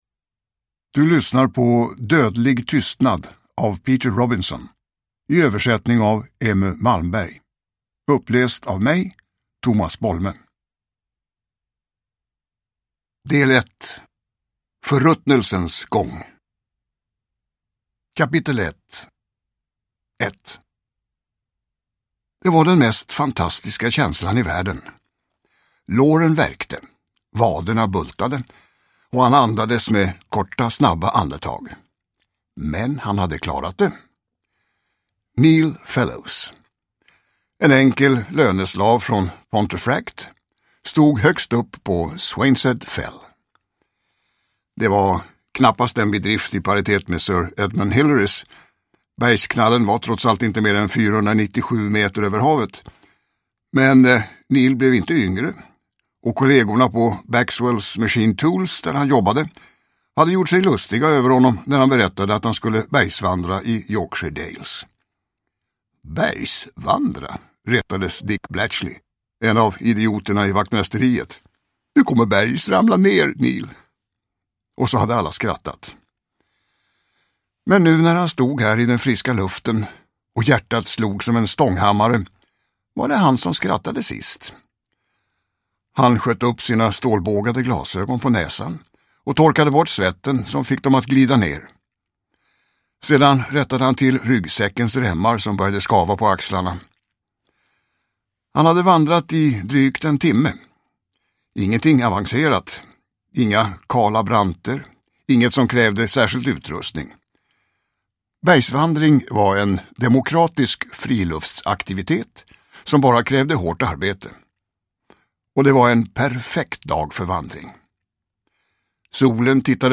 Dödlig tystnad – Ljudbok
Uppläsare: Tomas Bolme